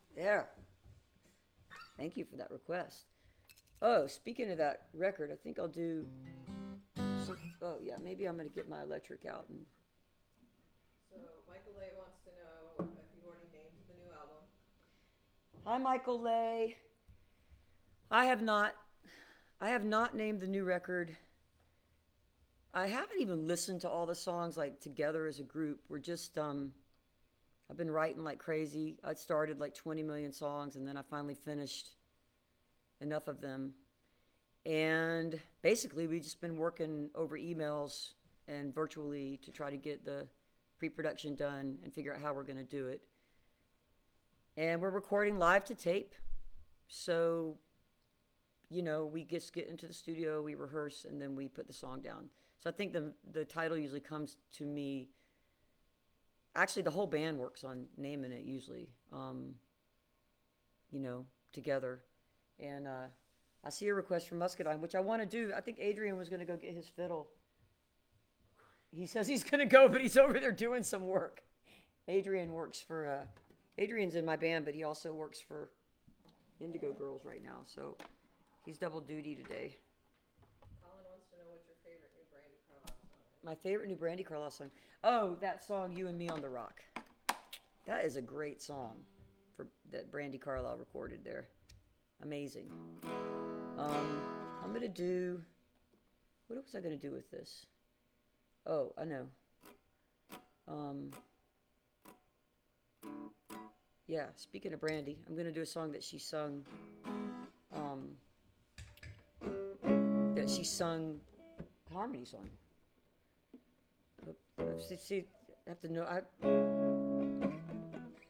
(captured from the facebook live stream)
07. talking with the crowd (1:58)